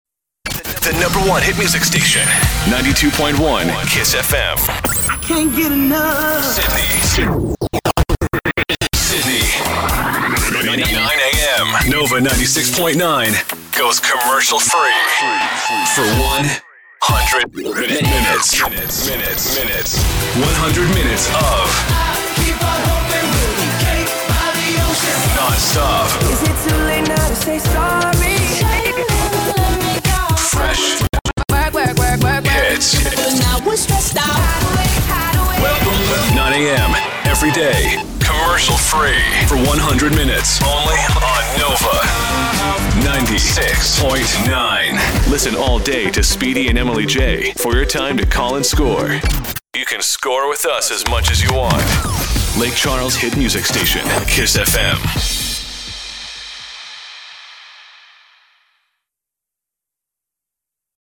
VO / Promo